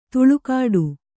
♪ tuḷukāḍu